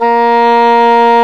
Index of /90_sSampleCDs/Roland LCDP04 Orchestral Winds/CMB_Wind Sects 1/CMB_Wind Sect 2
WND ENGHRN09.wav